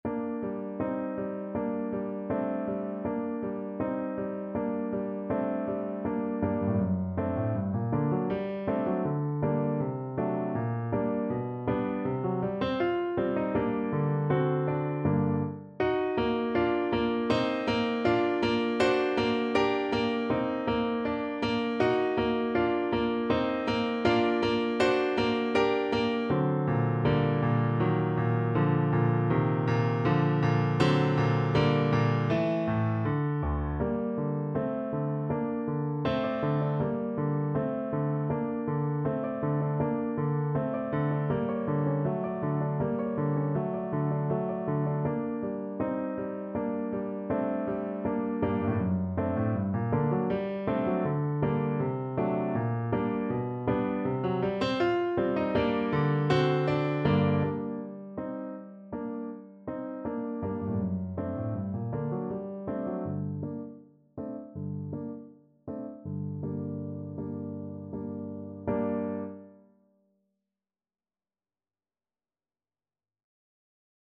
= 80 Andante espressivo
2/4 (View more 2/4 Music)
Classical (View more Classical Tenor Saxophone Music)